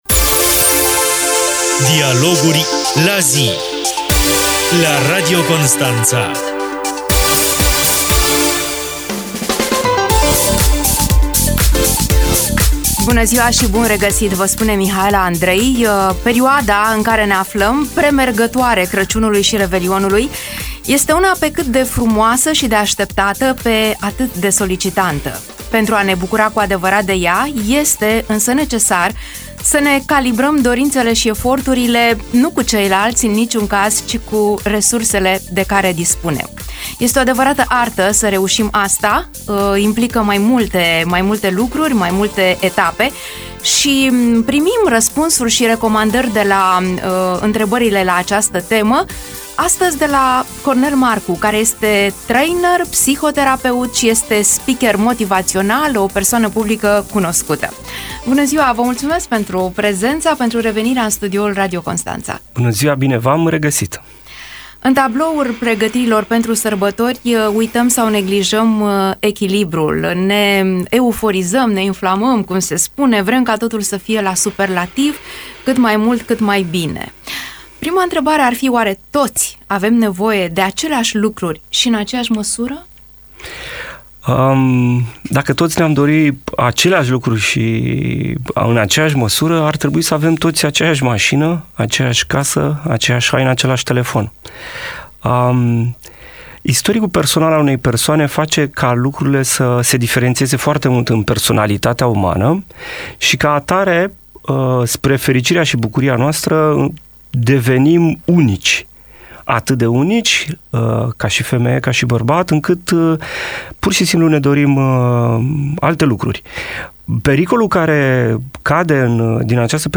Emisiunea